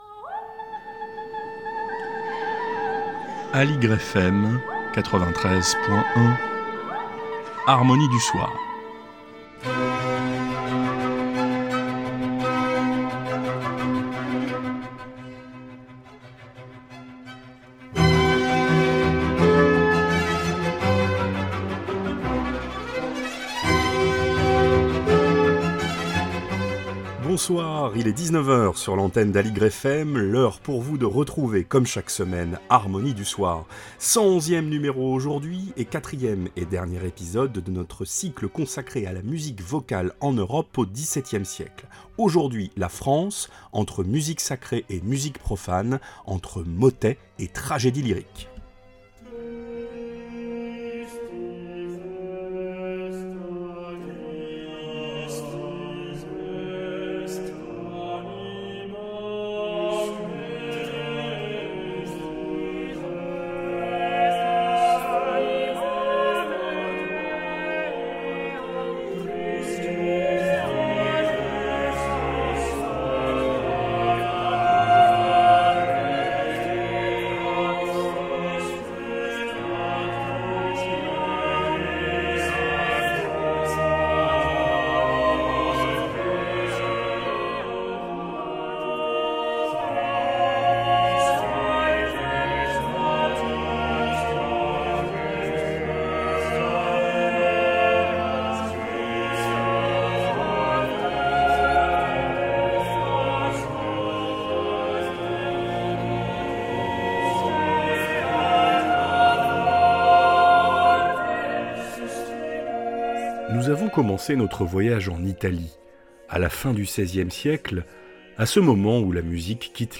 Quatrième et dernier épisode de notre série sur la musique vocale en Europe au 17ème siècle : la France, entre musique sacrée et musique profane, entre motets et tragédie lyrique. Au programme, des oeuvres de Lully, Charpentier, De Brossard, Robert, Veillot, Du Caurroy...